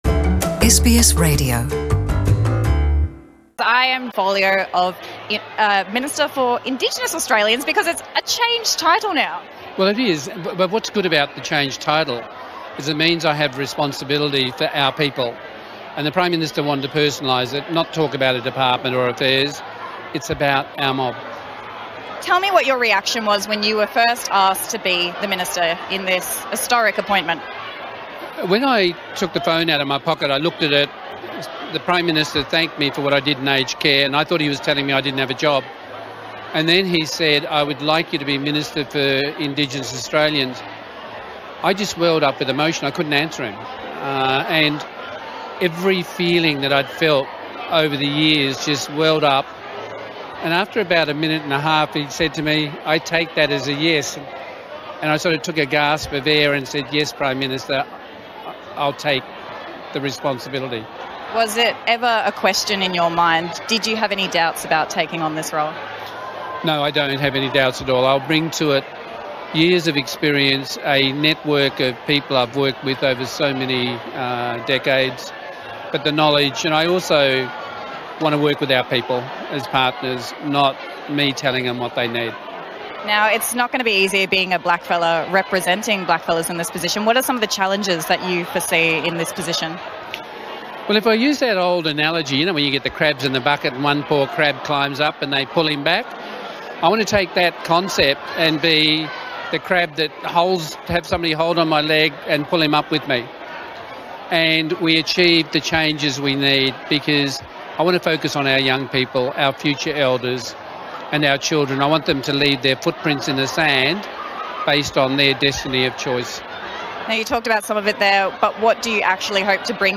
Ken Wyatt – Minister for Indigenous Australians at the National NAIDOC Awards Ceremony